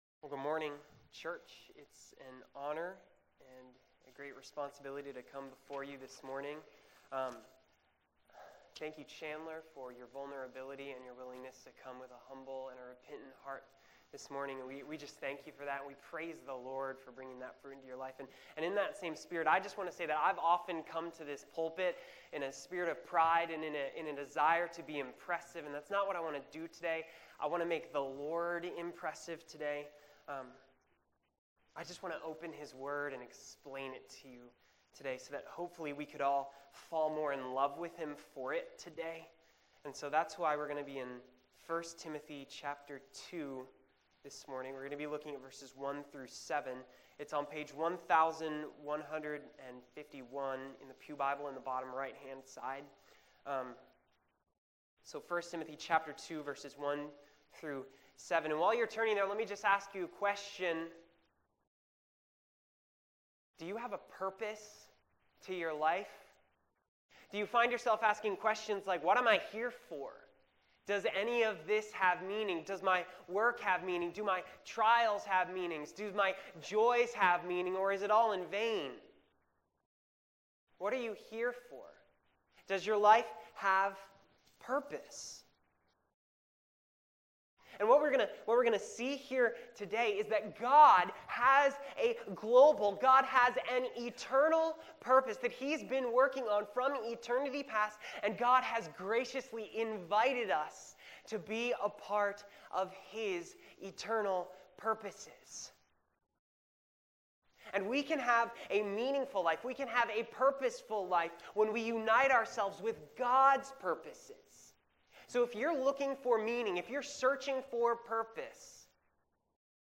January 24, 2016 AM Worship | Vine Street Baptist Church
This was the next message in multi-part sermon series on the book of 1st Timothy.